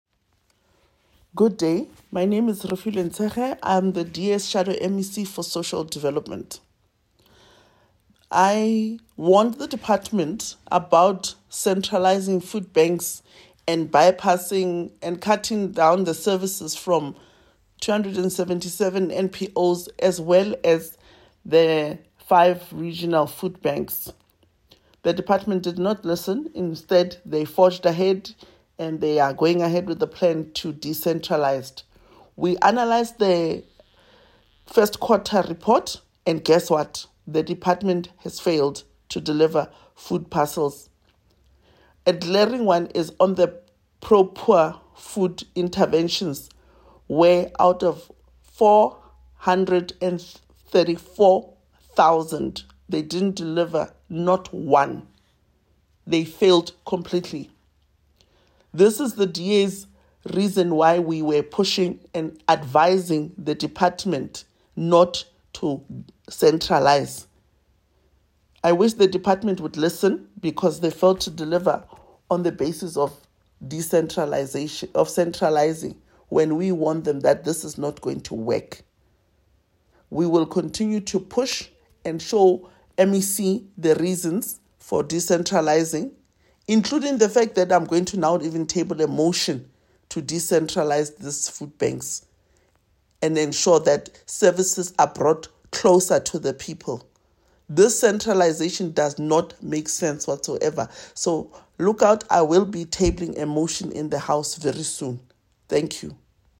Sesotho soundbites by Refiloe Nt’sekhe MPL.